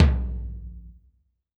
PTOM 1.wav